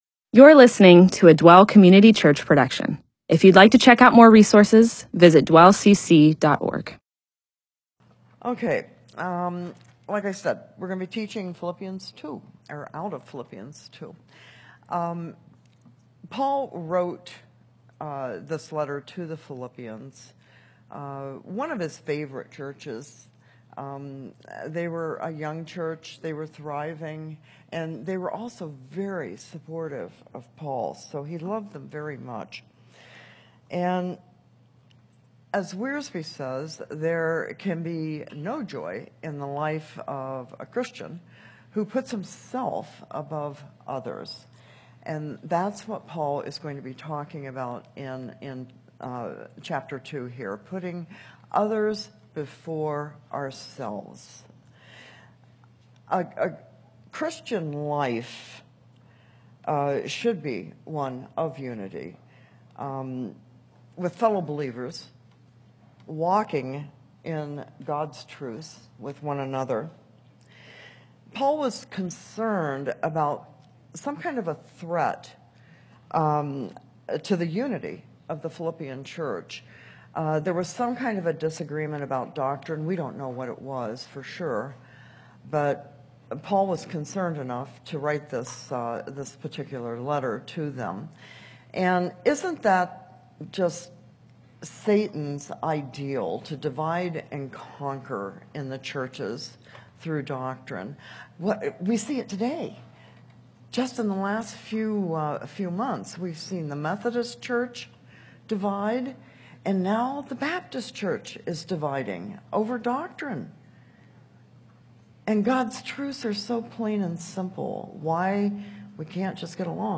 MP4/M4A audio recording of a Bible teaching/sermon/presentation about Philippians 2:1-15.